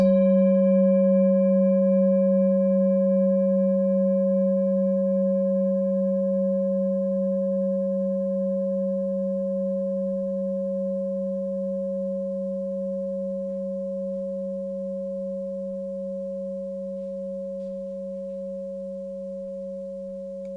Klangschale Orissa Nr.13
Klangschale-Gewicht: 1140g
Klangschale-Durchmesser: 19,8cm
Sie ist neu und wurde gezielt nach altem 7-Metalle-Rezept in Handarbeit gezogen und gehämmert.
(Ermittelt mit dem Filzklöppel)
Wenn man die Frequenz des Mittleren Sonnentags 24mal oktaviert, hört man sie bei 194,18 Hz.
Auf unseren Tonleiter entspricht er etwa dem "G".
klangschale-orissa-13.wav